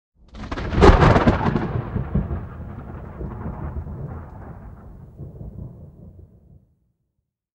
thunder_6.ogg